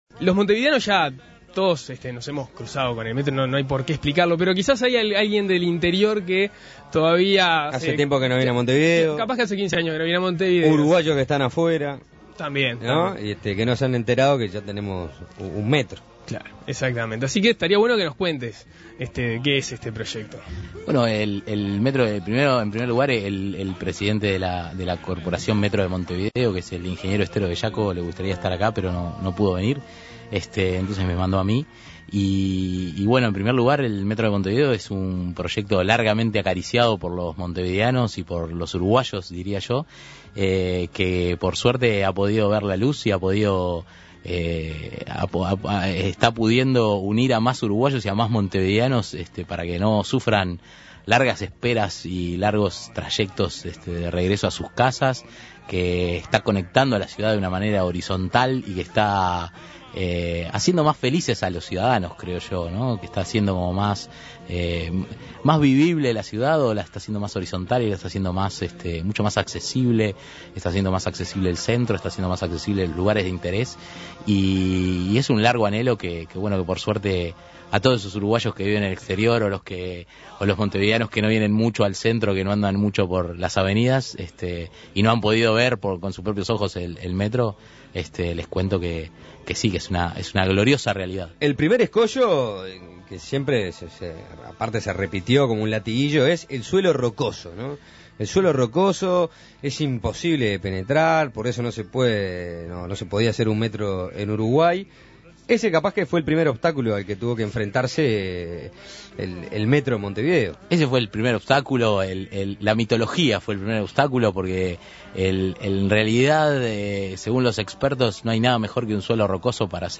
nos acompañó esta tarde en nuestros estudios